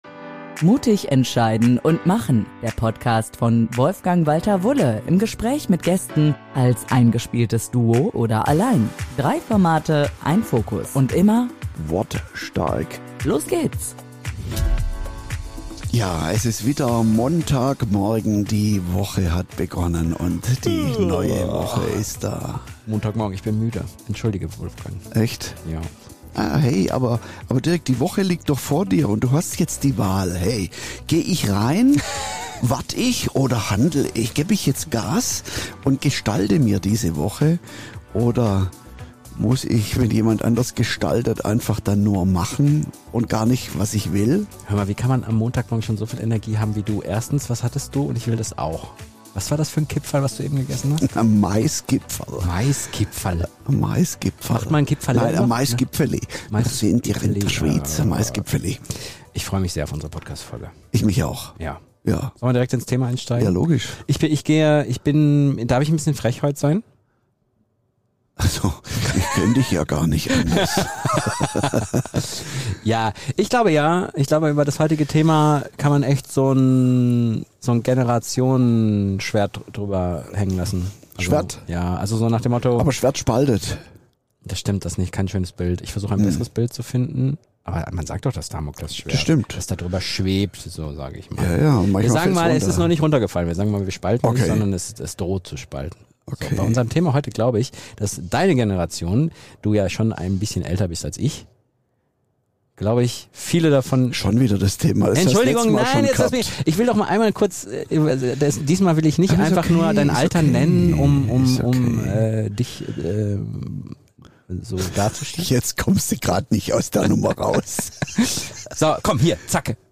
Eine Folge voller Klartext, Humor und Denkanstöße – von „Mais-Gipfeli“ bis zu echten Impulsen für die Arbeitswelt von morgen.